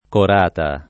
[ kor # ta ]